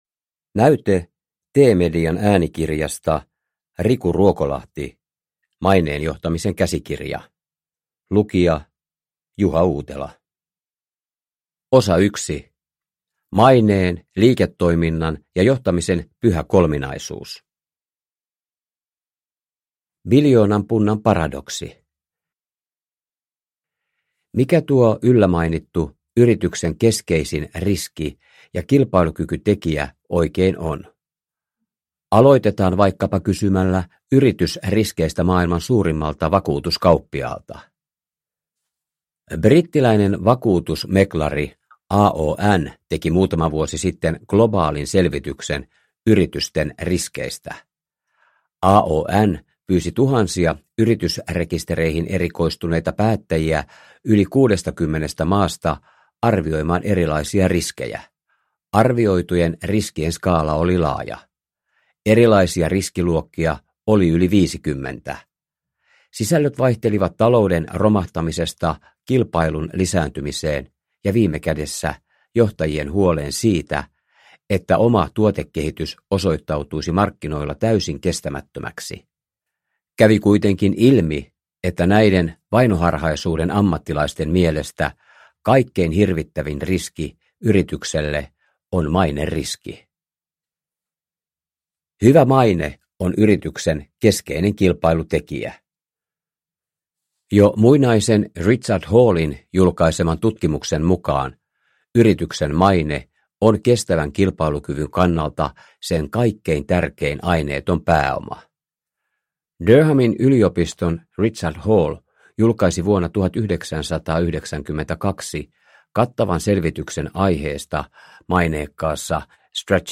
Maineen johtamisen käsikirja – Ljudbok – Laddas ner